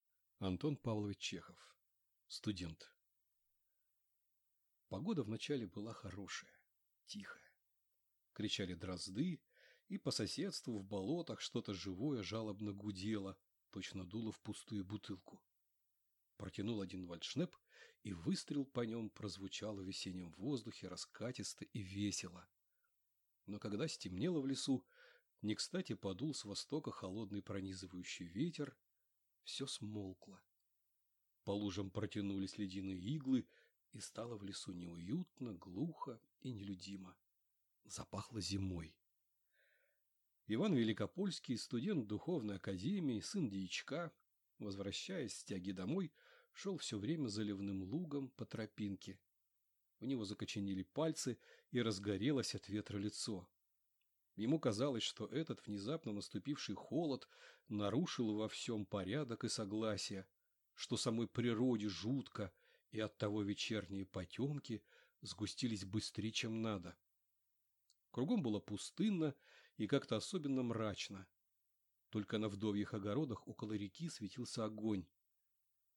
Аудиокнига Студент | Библиотека аудиокниг